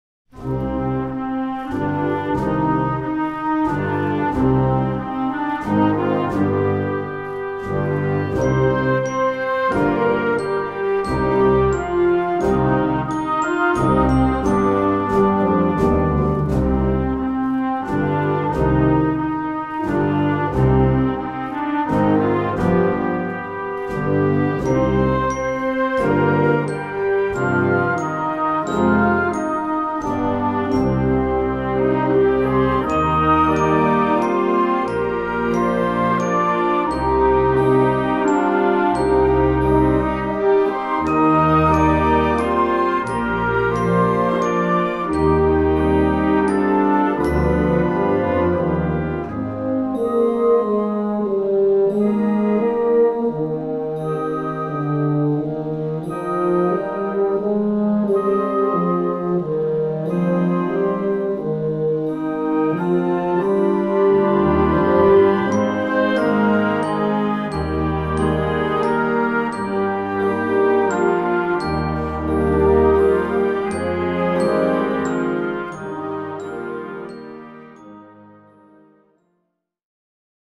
Orchestre Juniors